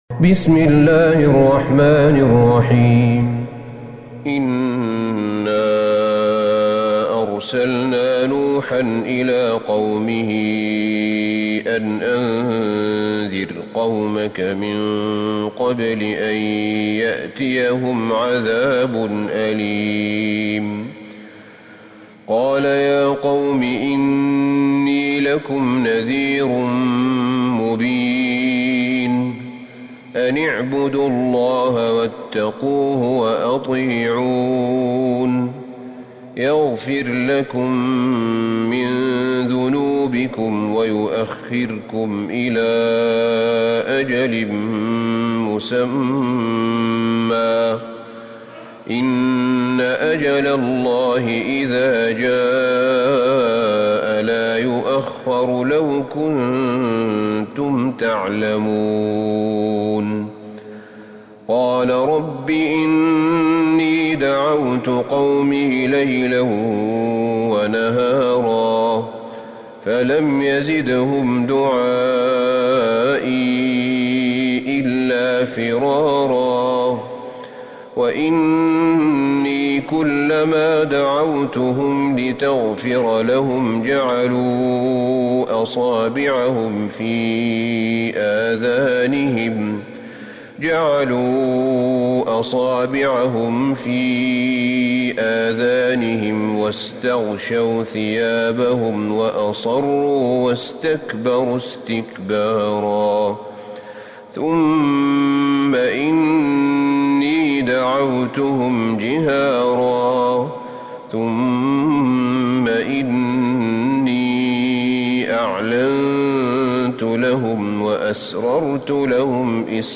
من الحرم النبوي